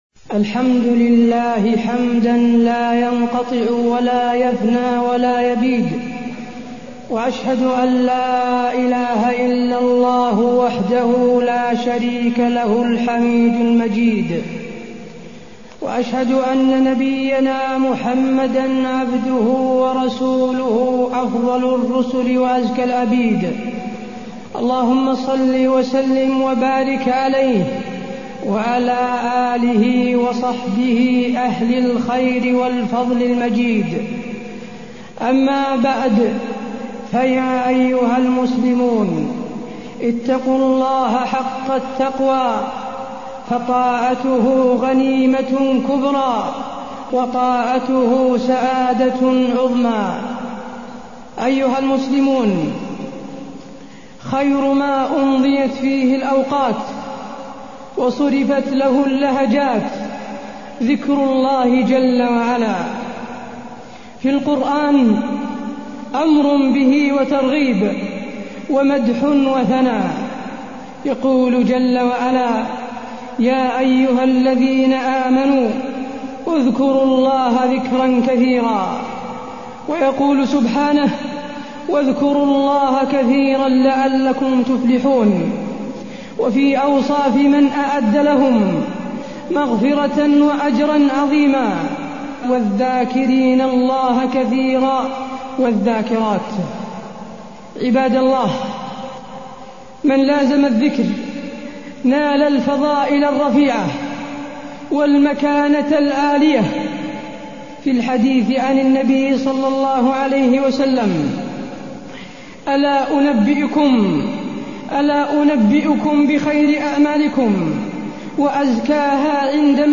تاريخ النشر ١٩ ربيع الثاني ١٤٢١ هـ المكان: المسجد النبوي الشيخ: فضيلة الشيخ د. حسين بن عبدالعزيز آل الشيخ فضيلة الشيخ د. حسين بن عبدالعزيز آل الشيخ الذكر وفوائده